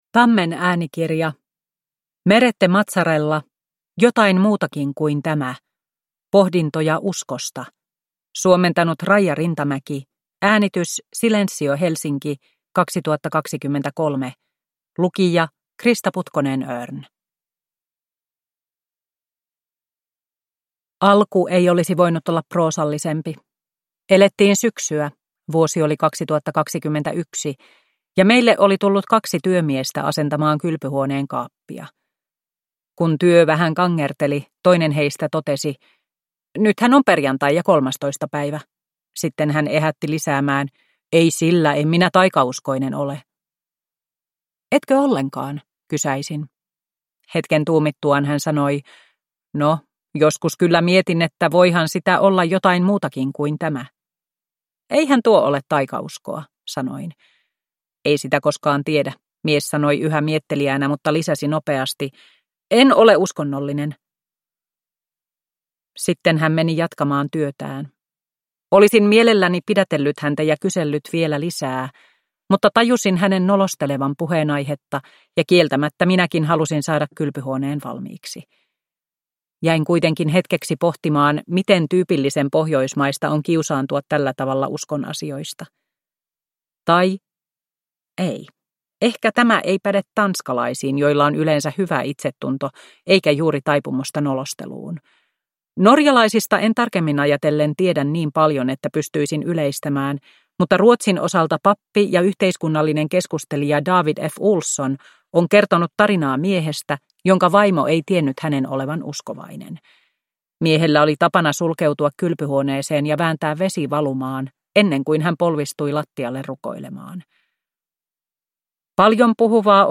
Jotain muutakin kuin tämä – Ljudbok – Laddas ner